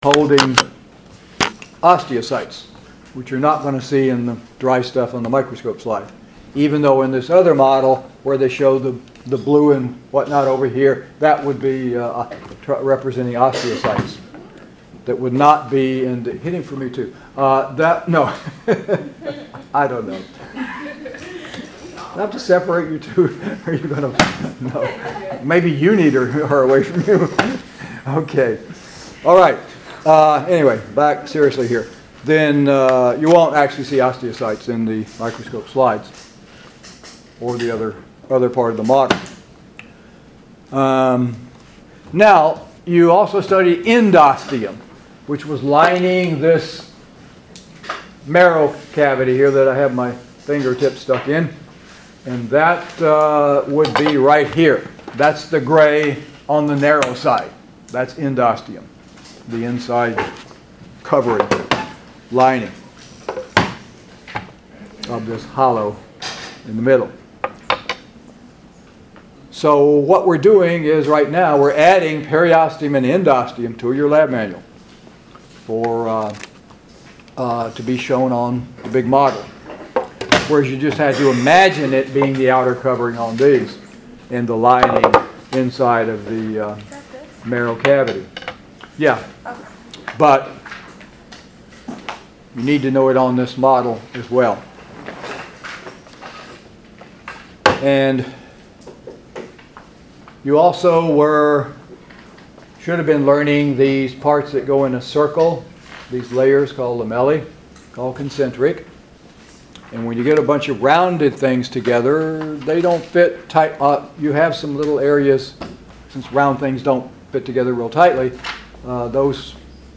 Lecture 9 - 27 September - opens in new window.